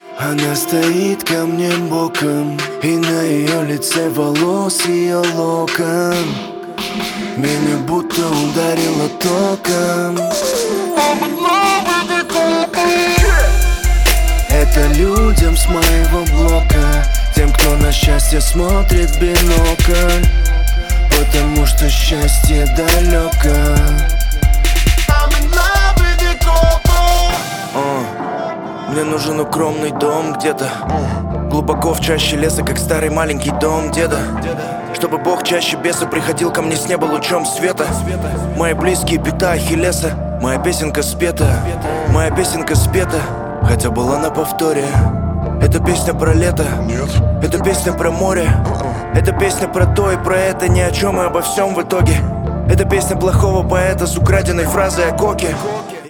• Качество: 128, Stereo
мужской вокал
русский рэп
качающие